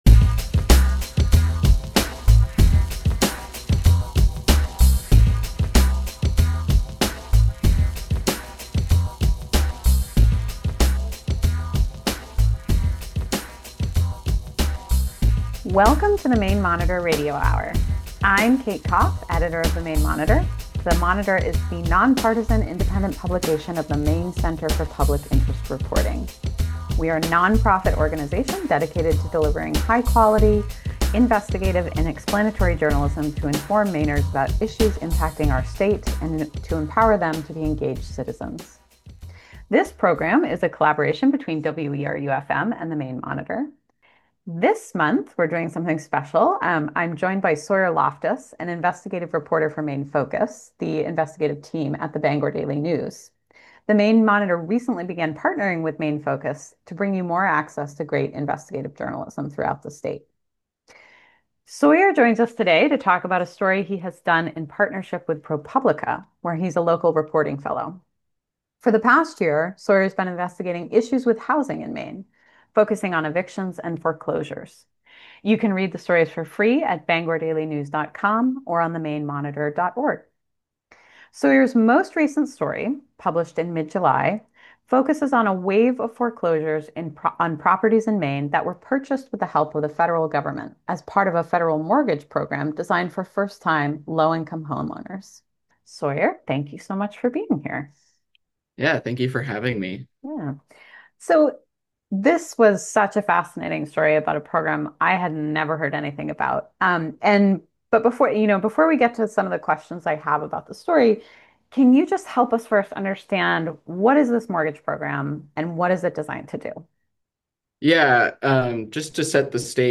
The Maine Monitor Radio Hour is a collaboration between WERU-FM and the Maine Monitor, the nonpartisan, independent publication of the Maine Center for Public Interest Reporting.